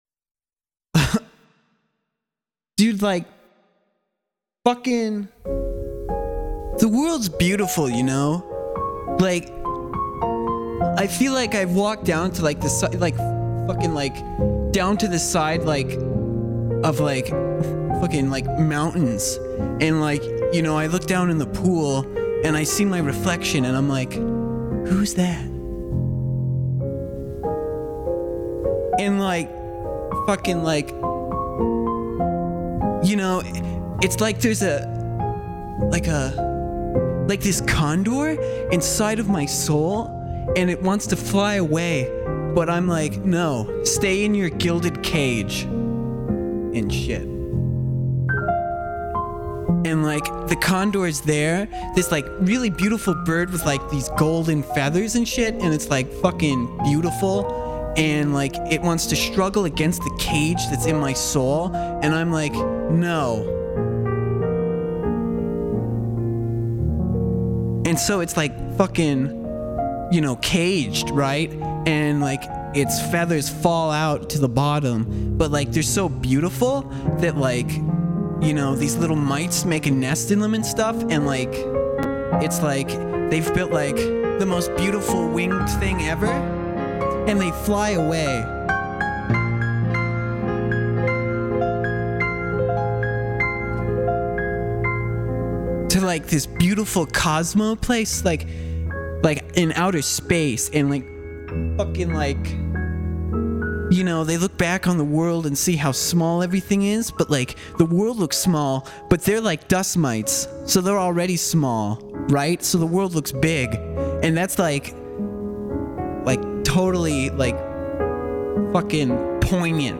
I do a lot of experimental soundscapes and some improvised prose poems.
My improv prose is usually pretty esoteric and very free form.  I like to do kind of a stream-of-consciousness type deal.